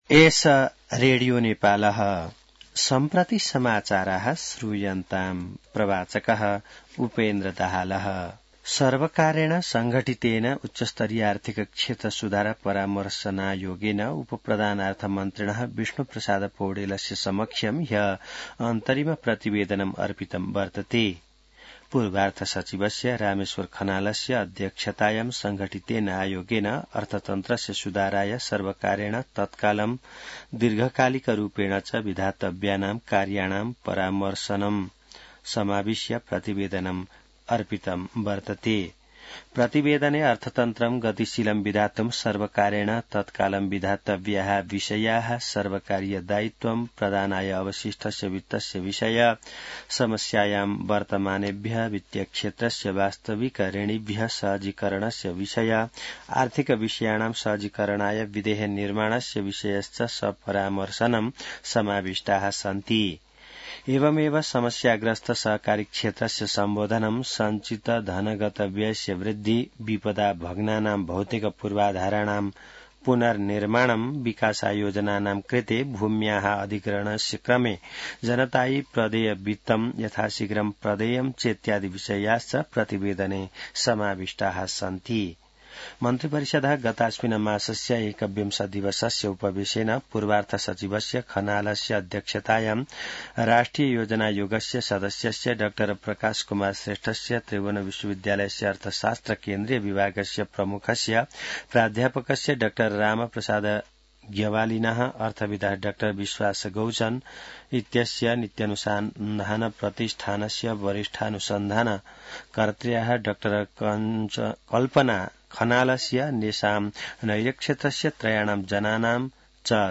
संस्कृत समाचार : २६ पुष , २०८१